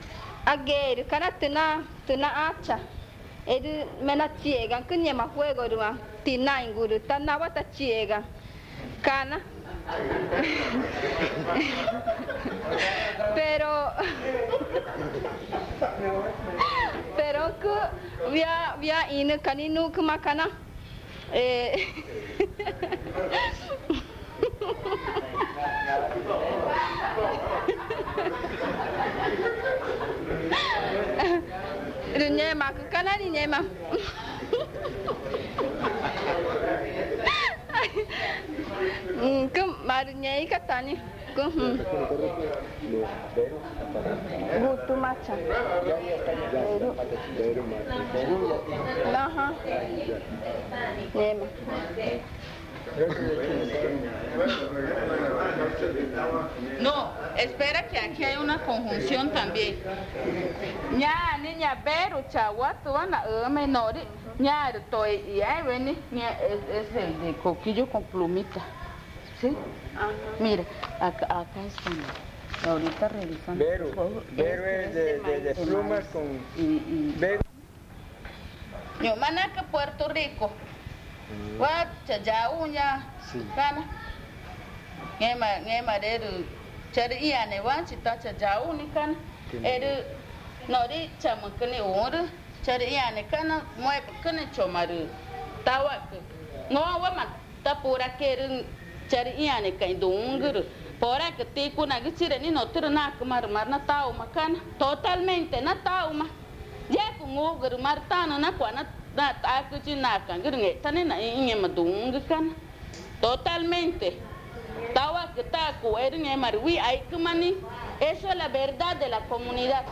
Tipisca, Amazonas (Colombia)